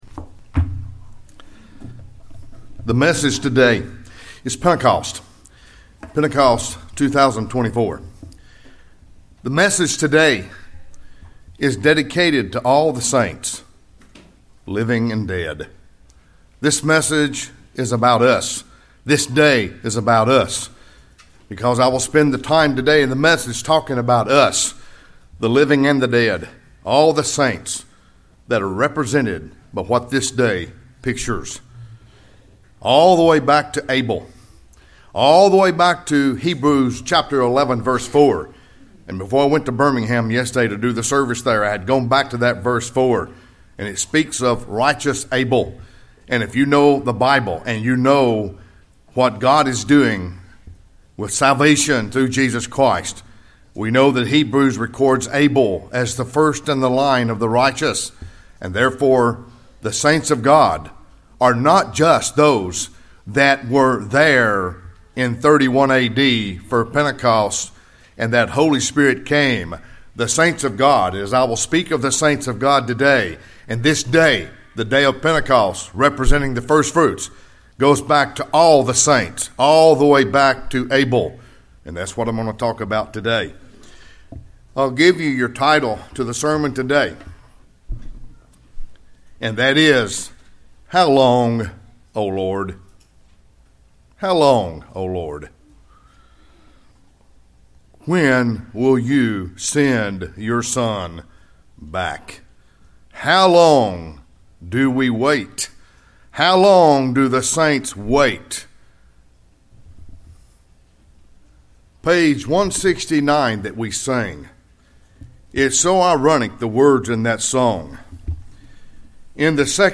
A very personal message on the Day of Pentecost, dedicated to every saint of God, living and dead.